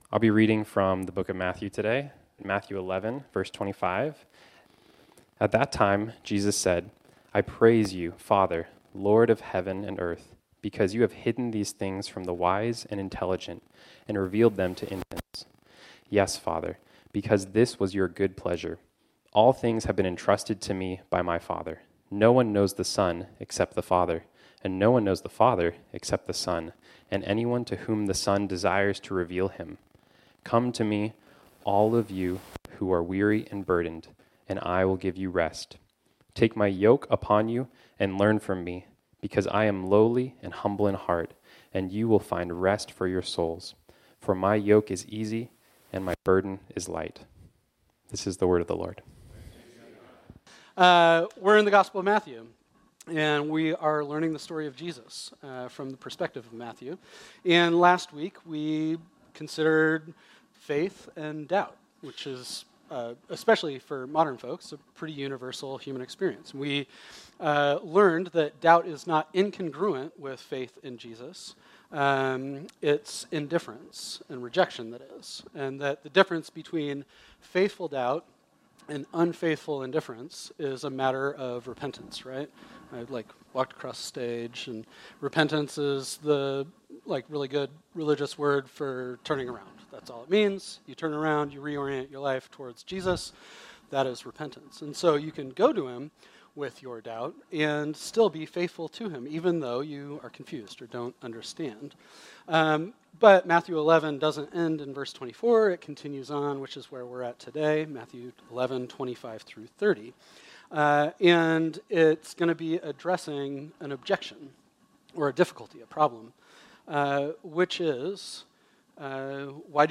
This sermon was originally preached on Sunday, May 19, 2024.